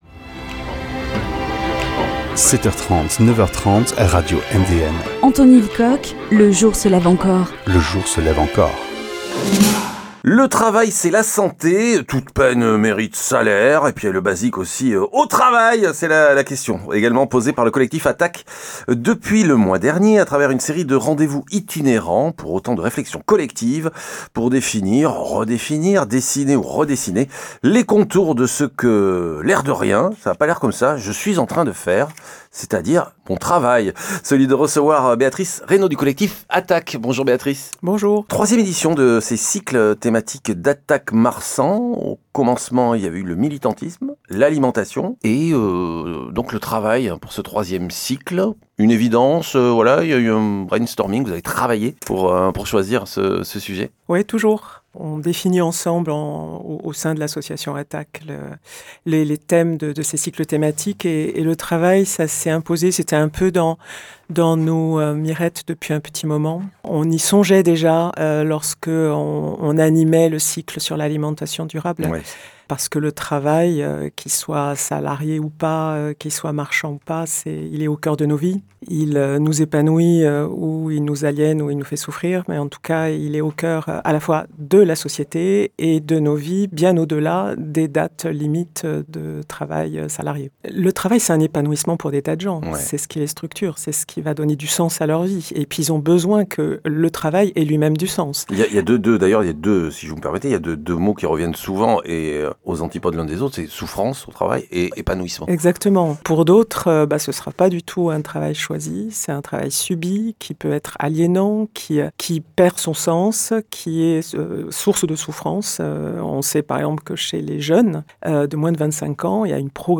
Invitée de » Le jour se lève encore »